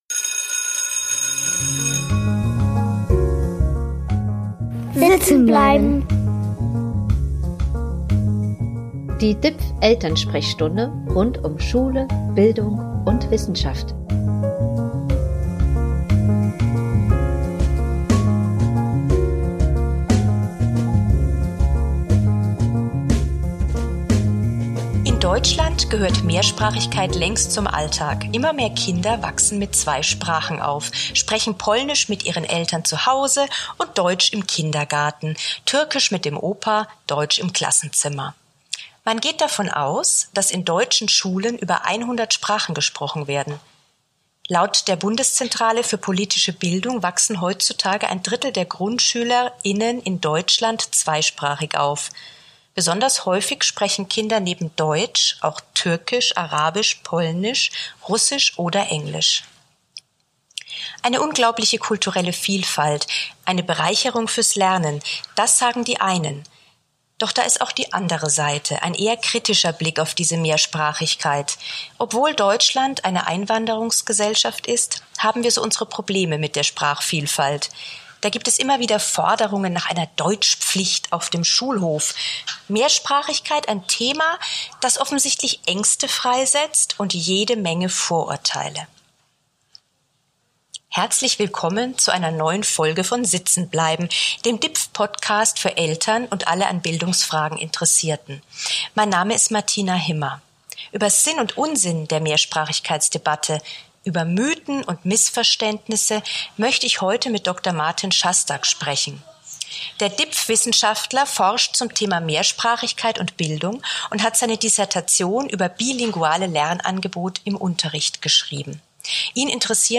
Für den Jingle haben wir Sounds verwendet, die unter einer CC-Lizenz stehen:
Schulklingel